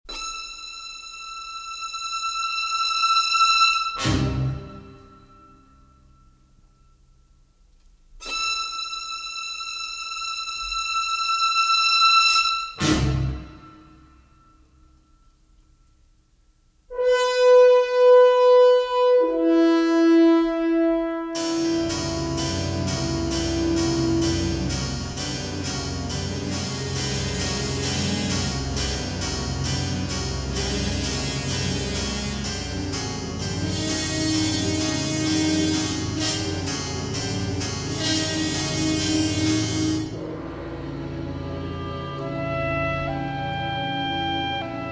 with notable use of harpsichord for sinister effect.
a soaring love theme